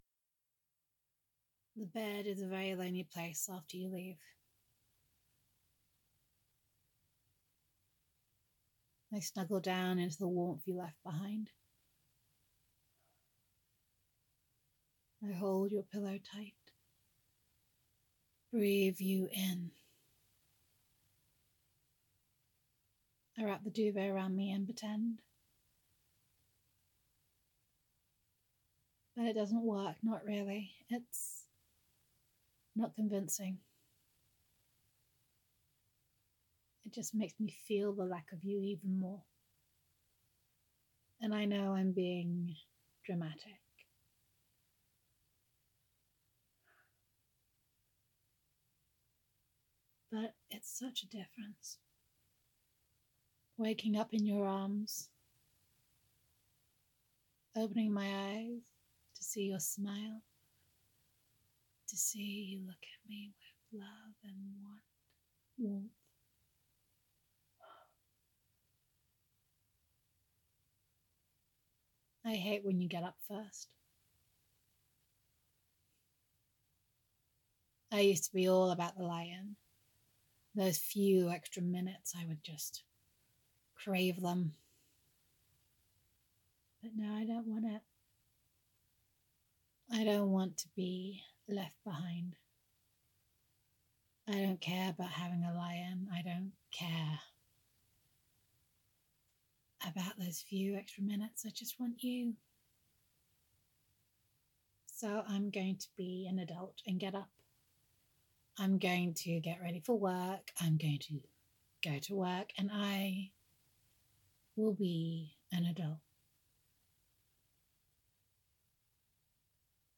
[F4A] Oh, What You Have Done to Me? [Pouting][The Bed Is a Lonely Place Without You][Loving Girlfriend Voicemail]